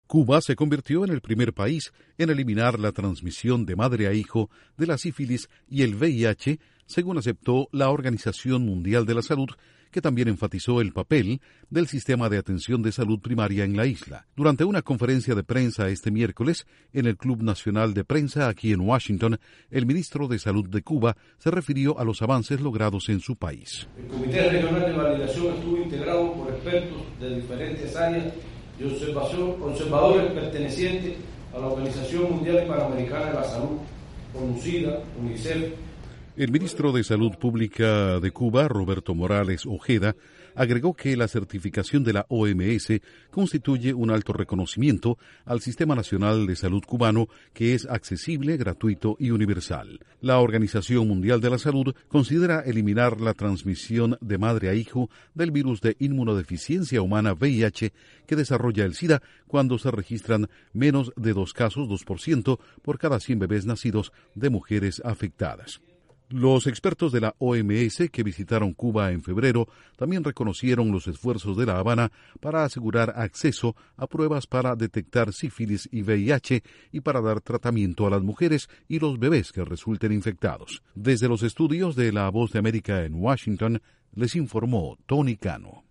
El Ministro de Salud de Cuba reconoce en la capital estadounidense el avance de su país en el campo de salud, en especial contra el VIH. Informa desde los estudios de la Voz de América en Washington